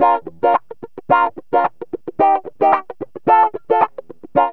GTR 7 A#M110.wav